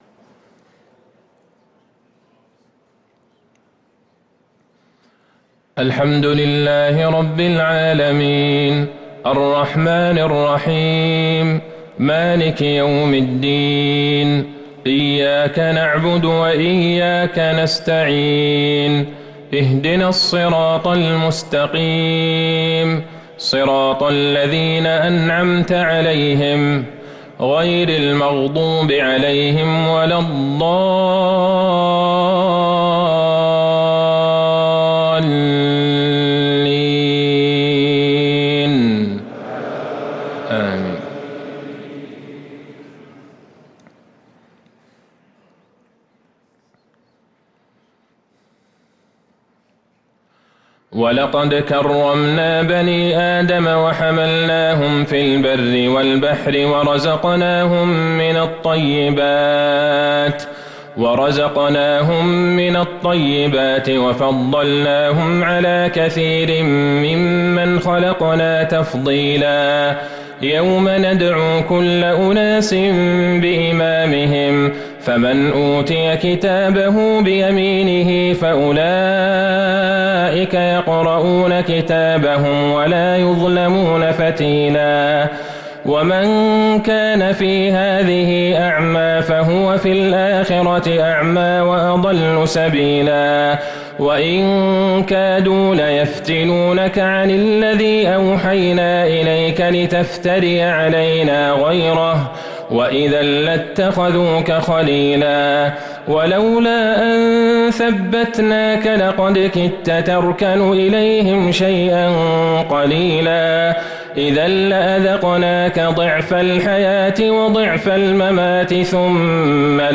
صلاة العشاء للقارئ عبدالله البعيجان 15 رجب 1444 هـ
تِلَاوَات الْحَرَمَيْن .